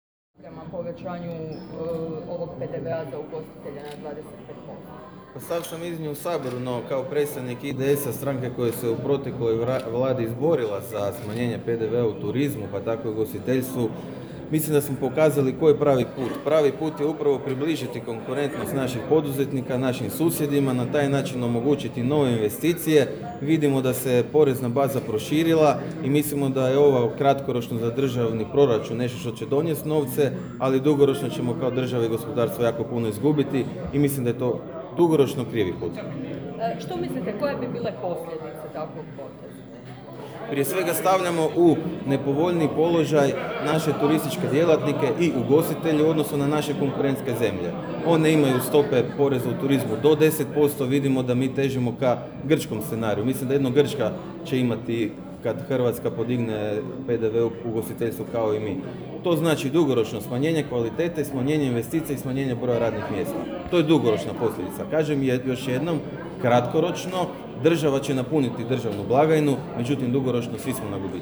U ponedjeljak, 21.11.2016. u pazinskom Spomen domu okupilo se više stotina ugostitelja na skupu protiv povećanja PDV-a u ugostiteljstvu.